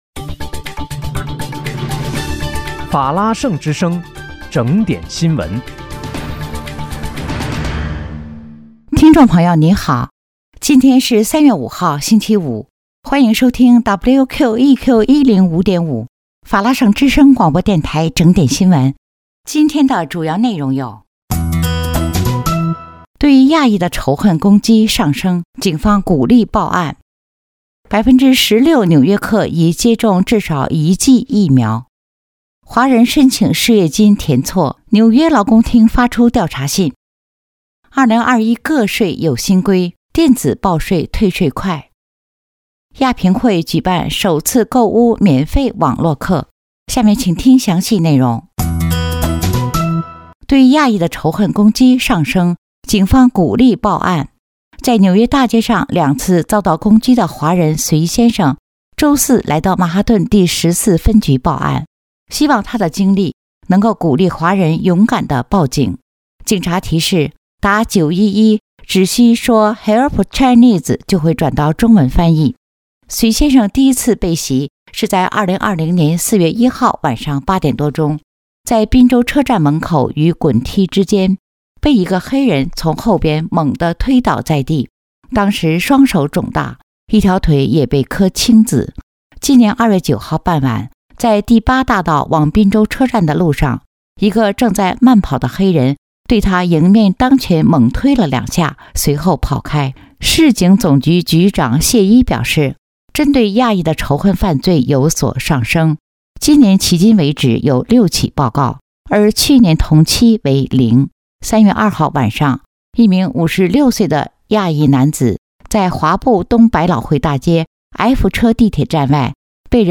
3月5日（星期五）纽约整点新闻